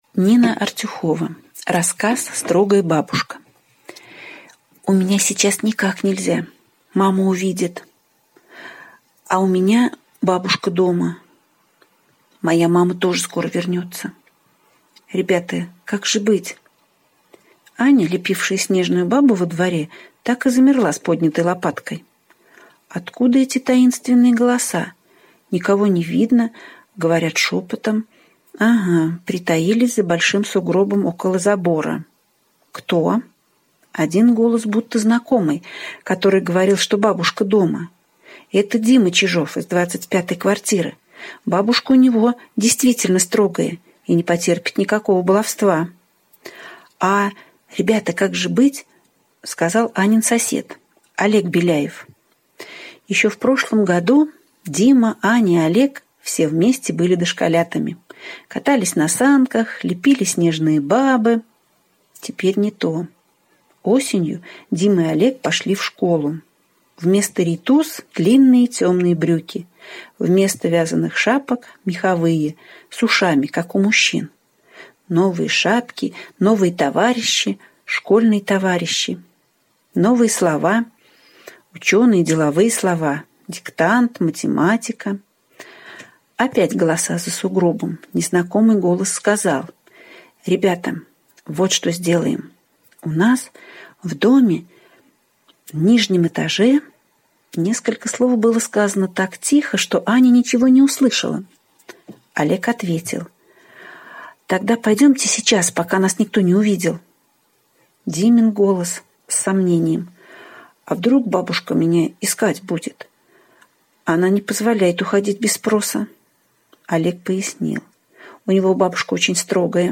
Строгая бабушка - аудио рассказ Артюховой - слушать онлайн